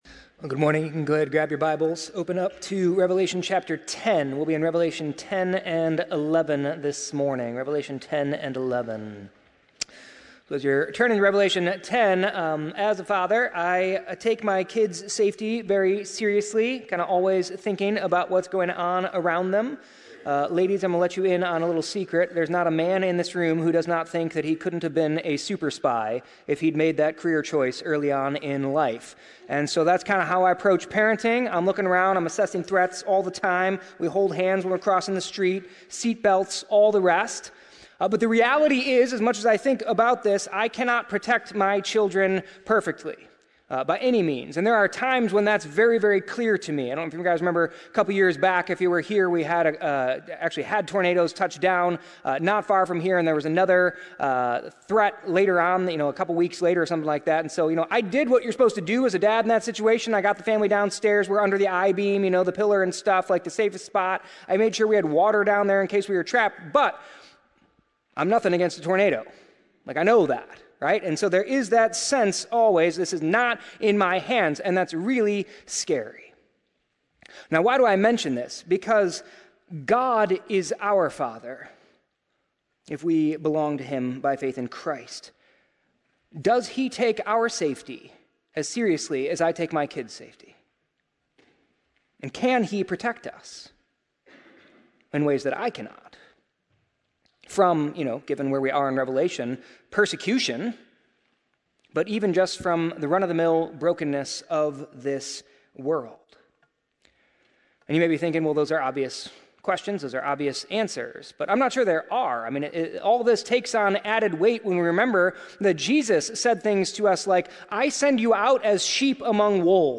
The sermon discusses God’s protection and the church’s role in proclaiming the gospel, even in the face of persecution. It explores the symbolism in Revelation 10-11, including the mighty angel, the two witnesses, and the sounding of the seventh trumpet.